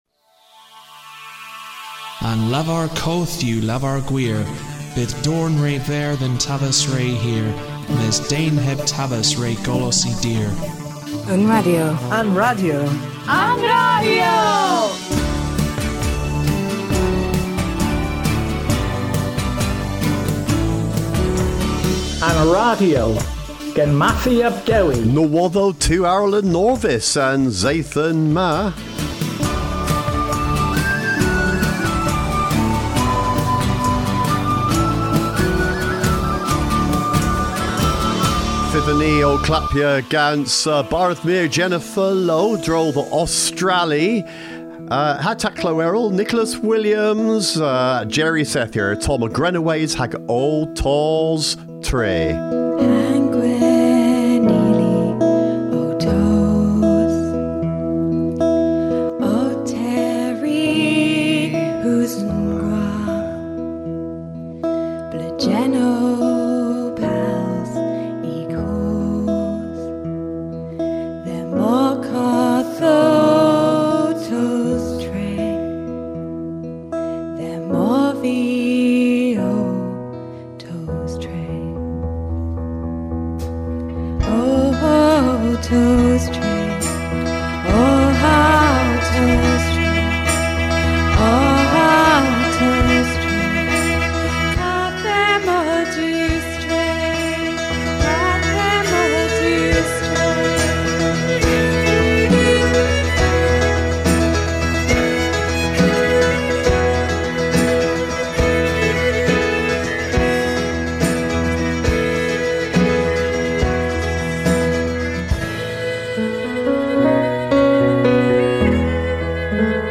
AGAN TOWLENNOW / OUR PROGRAMMES An Radyo is a weekly one hour radio show playing Cornish and Celtic music, presented in Cornish. We also have news in Cornish and sometimes an interview.